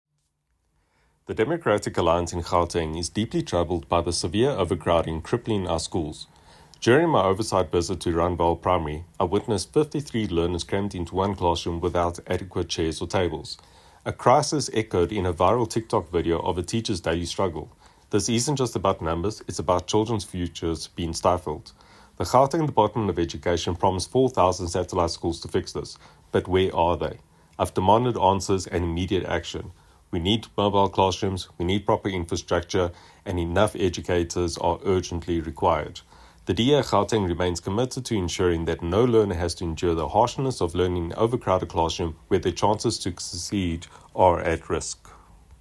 English soundbite by Sergio Isa Dos Santos MPL.